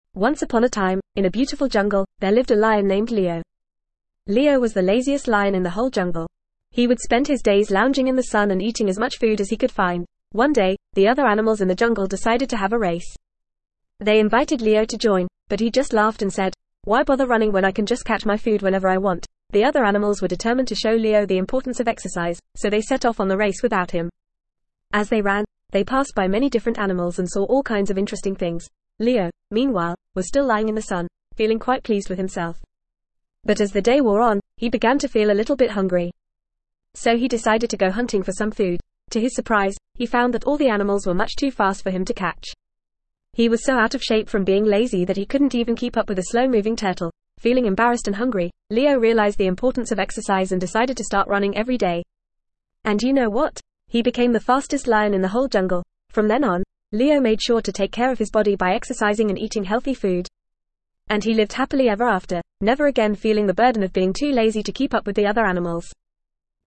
Fast
ESL-Short-Stories-for-Kids-FAST-reading-The-Lazy-Lion.mp3